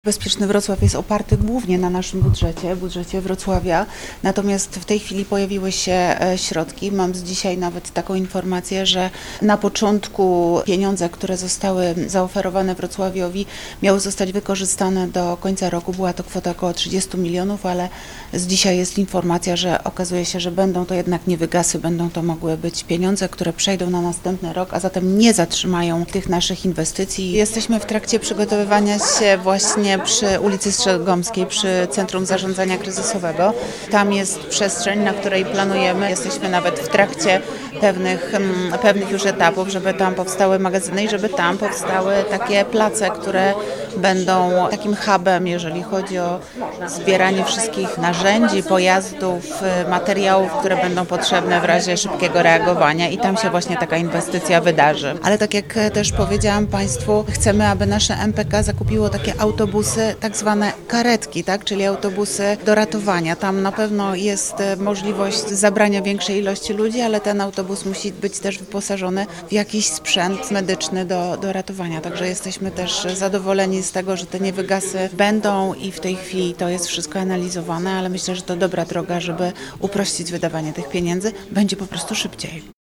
Jak tłumaczy Renata Granowska wiceprezydent Wrocławia, środki na szkolenia pochodzą głównie z budżetu miasta. Natomiast Wrocław otrzymał też 30 mln zł na działania z zakresu Ochrony Ludności i Obrony Cywilnej od Wojewody Dolnośląskiego.